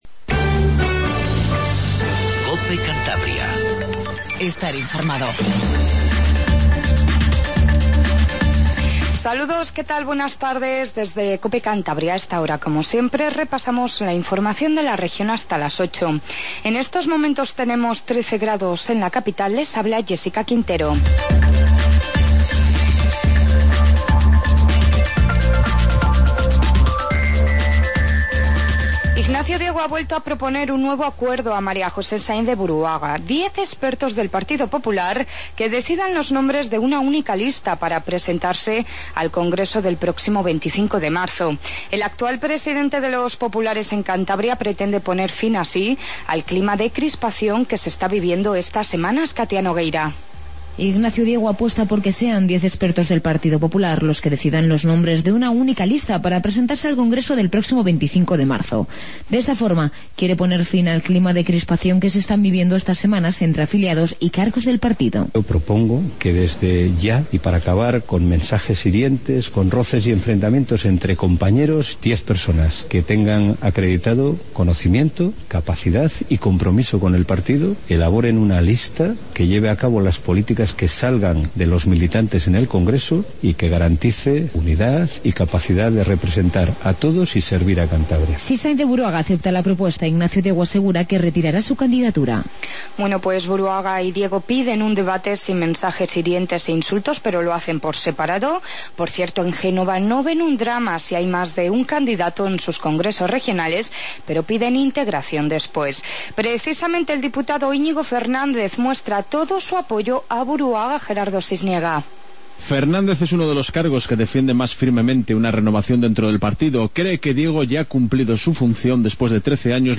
INFORMATIVO DE TARDE 19:50